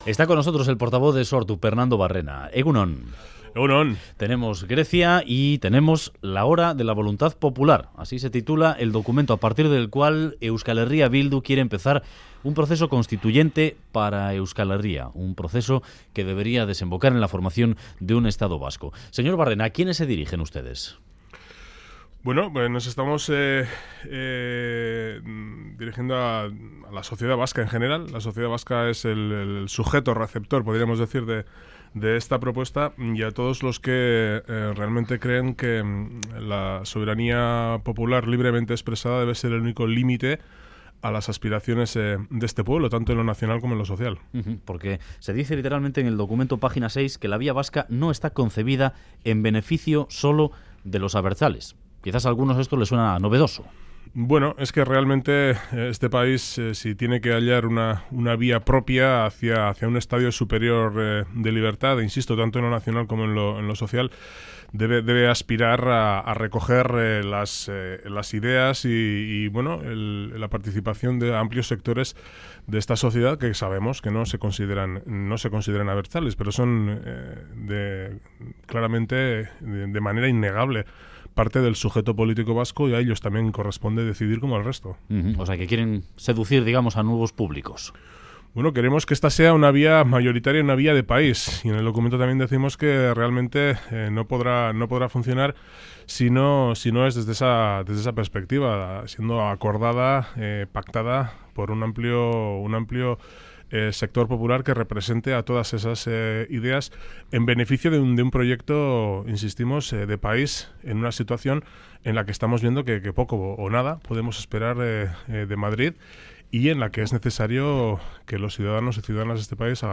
Radio Euskadi BOULEVARD Barrena: 'Queremos que Euskal Bidea sea una vía mayoritaria y de país' Última actualización: 26/01/2015 10:00 (UTC+1) En entrevista al Boulevard de Radio Euskadi, el portavoz de Sortu, Pernando Barrena, ha dicho que quieren que la propuesta hacia la independencia "Euskal Bidea", presentada por Euskal Herria Bildu, sea una vía mayoritaria y de país. Ha expresado su esperanza en un pronto cambio político en Navarra y, sobre el triunfo de Syriza en Grecia, lo ha valorado positivamente y espera que marque el inicio de un cambio de rumbo político en Europa.